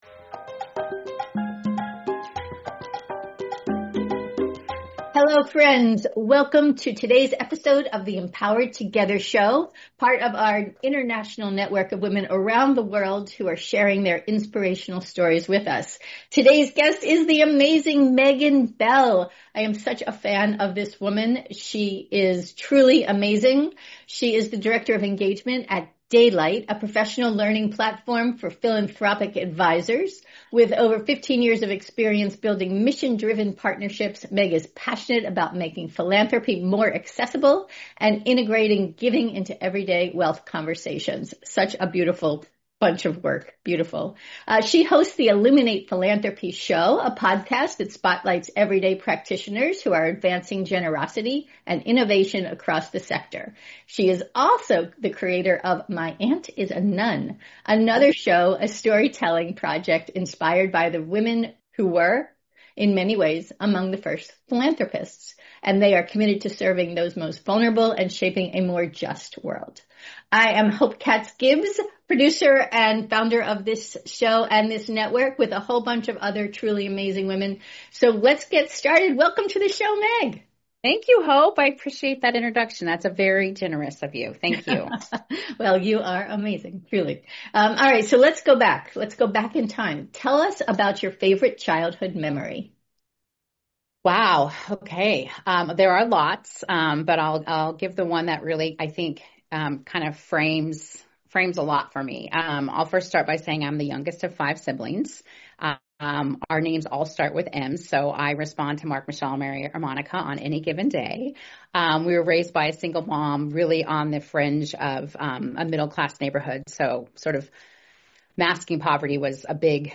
Before we jump into our Q&A, here’s a little about this truly amazing woman .